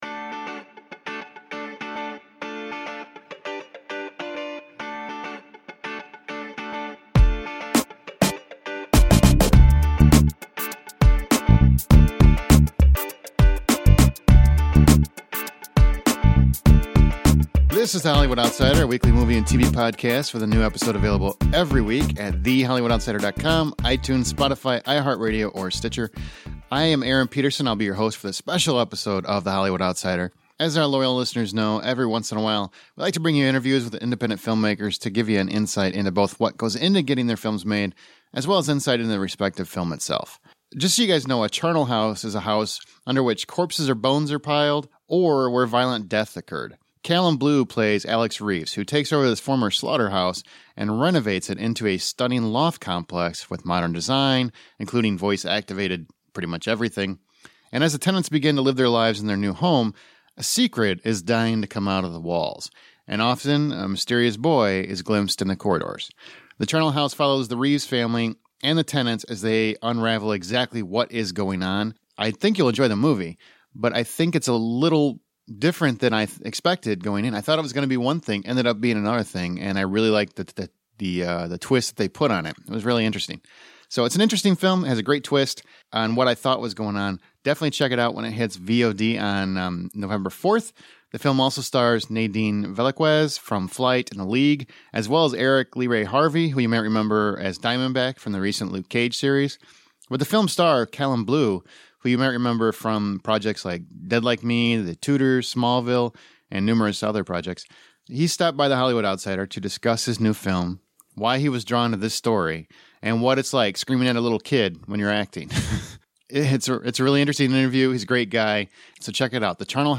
Listen to our exclusive interview with The Charnel House star, Callum Blue.